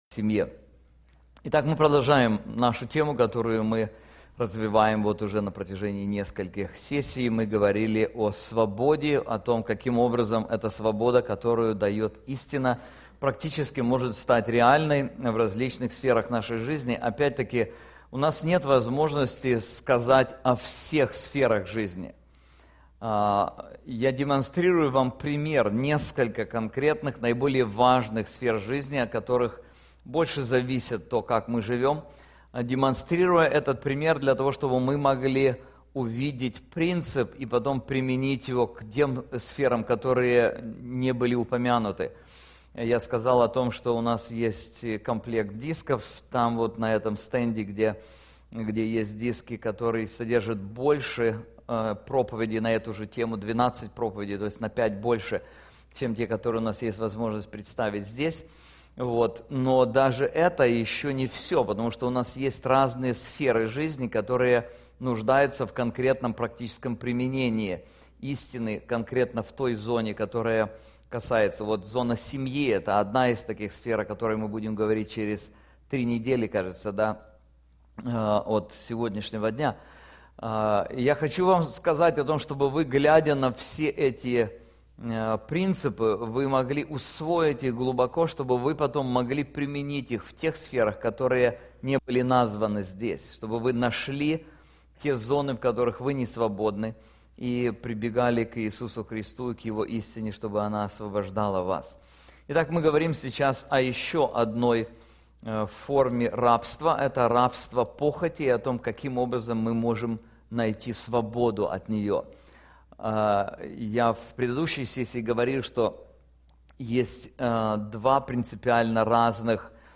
Конференции Истинно свободны